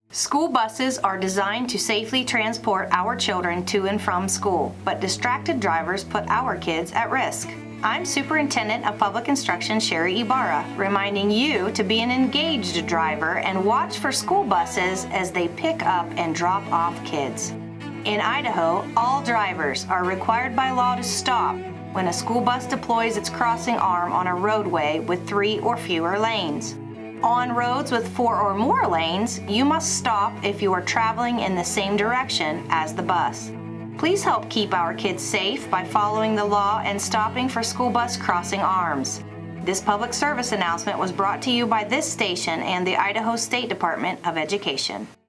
public service announcement on Wednesday.
Crossing-Arm-PSA-Supt-Public-Instruction-Sherri-Ybarra.wav